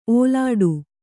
♪ ōlāḍu